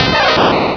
Cri de Pikachu dans Pokémon Rubis et Saphir.
Cri_0025_RS.ogg